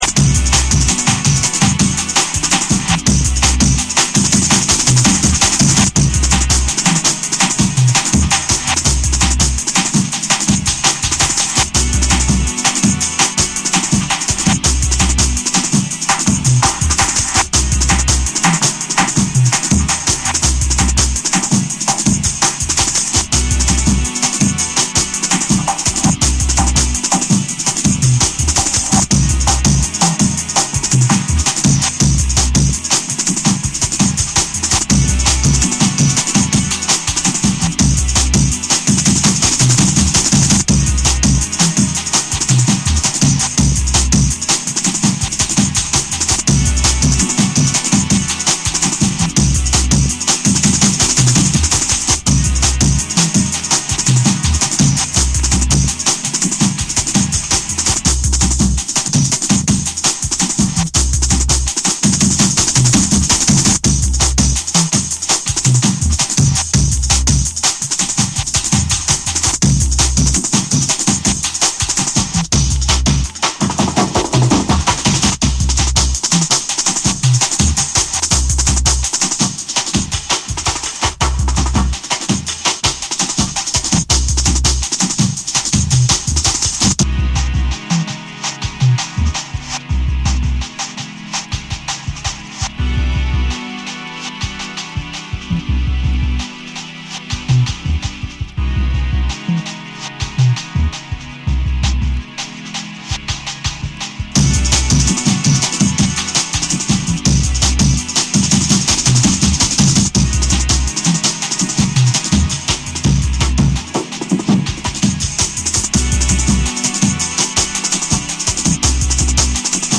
浮遊感あるシンセと緻密なビート構築、ジャジーな要素も交えたジャングル重要作として今なお色褪せない完成度。
JUNGLE & DRUM'N BASS